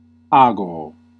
Ääntäminen
IPA : /ˈkɒndʌkt/